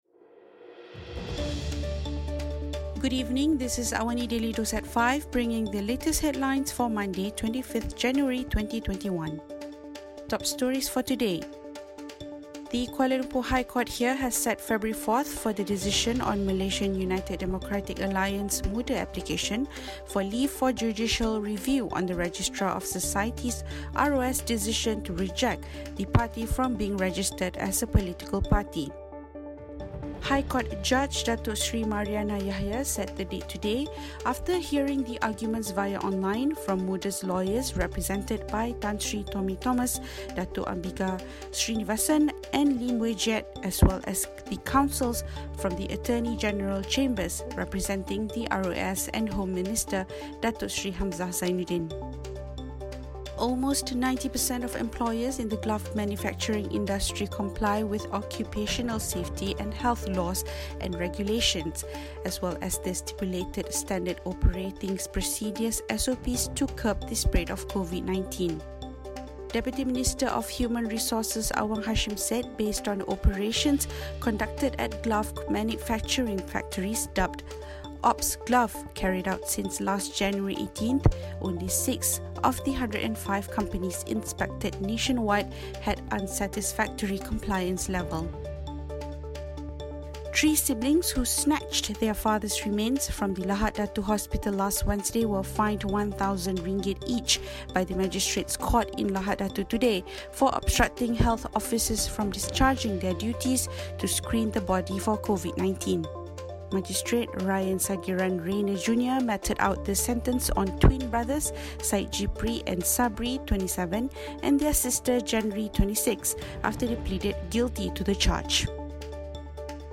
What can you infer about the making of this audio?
Also, Eleven Chinese gold miners trapped underground after an explosion were rescued much earlier than expected, thanks to steel pipes supporting blockages in the mine shaft. Listen to the top stories of the day, reporting from Astro AWANI newsroom — all in 3 minutes.